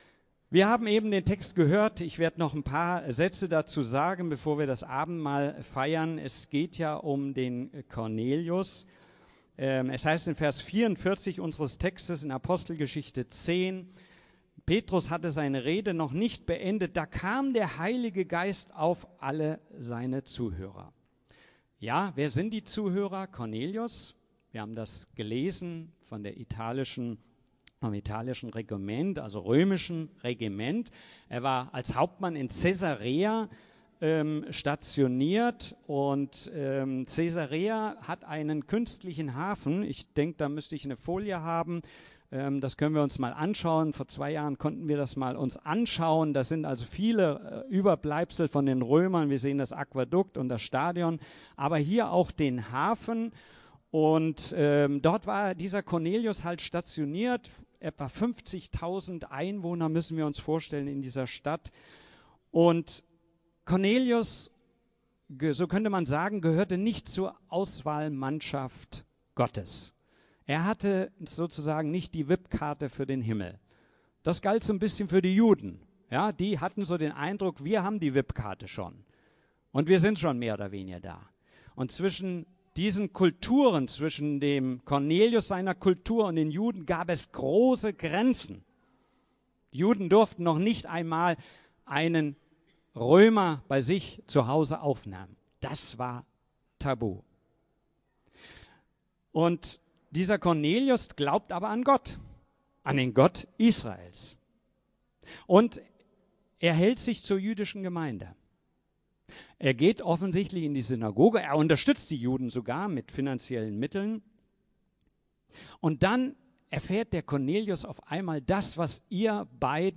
Predigten FeG Schwerin Podcast